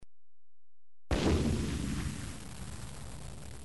دانلود صدای بمب و موشک 2 از ساعد نیوز با لینک مستقیم و کیفیت بالا
جلوه های صوتی